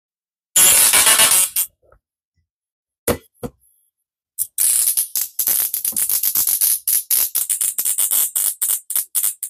Lightning + Orange + Banana sound effects free download